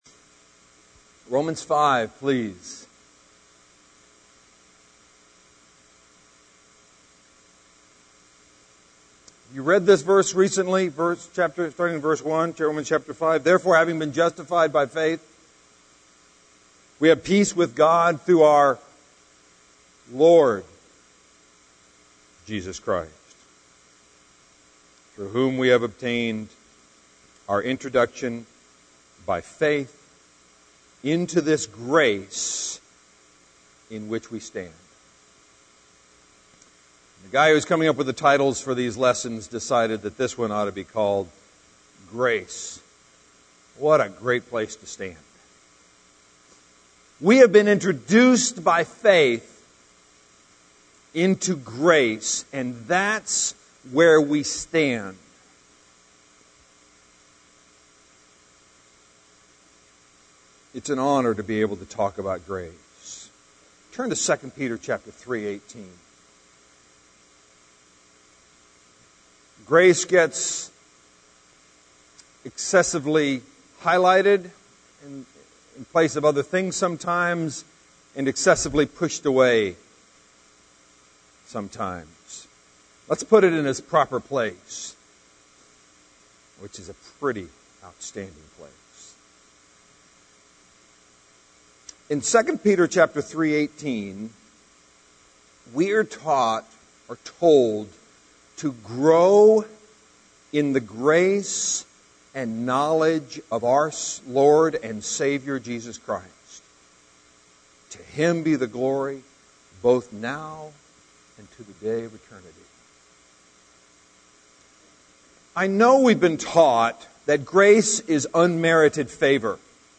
Lectureship - 2007
Sermons